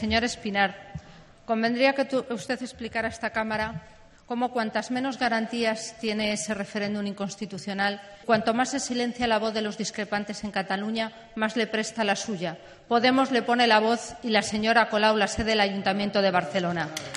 De esta manera lo ha manifestado en respuesta a una pregunta en la sesión de control al Gobierno del Senado del portavoz de Unidos Podemos, Ramón Espinar, sobre si el Ejecutivo ha encontrado ya una solución política dialogada para Cataluña.